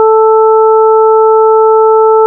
1. のこぎり波の第3フーリエ部分和：2π(sin880πt-12sin1760πt+13sin2640πt)【By Analog Devices (旧Linear Technology) LTspice。補足資料(p.6に波形の図あり)
sawtooth_f-440_E-1_FPS3.wav